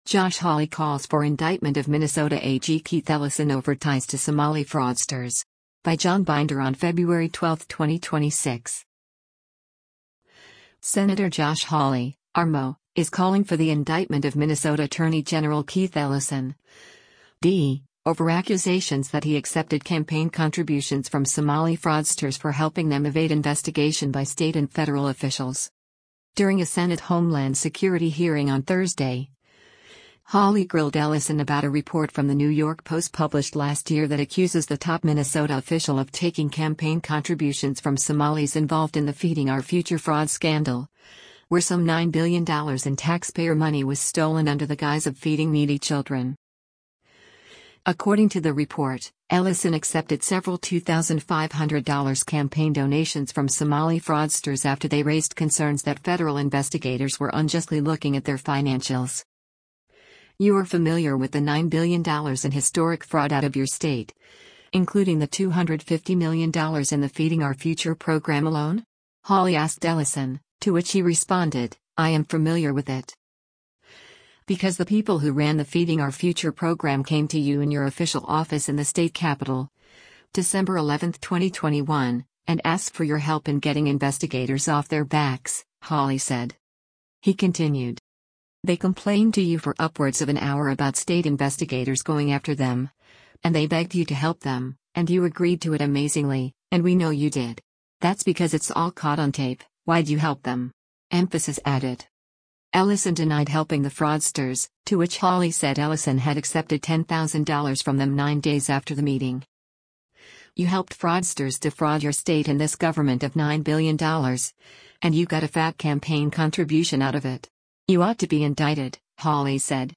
During a Senate Homeland Security hearing on Thursday, Hawley grilled Ellison about a report from the New York Post published last year that accuses the top Minnesota official of taking campaign contributions from Somalis involved in the Feeding Our Future fraud scandal, where some $9 billion in taxpayer money was stolen under the guise of feeding needy children.